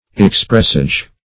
\Ex*press"age\ ([e^]ks*pr[e^]s"[asl]j; 48)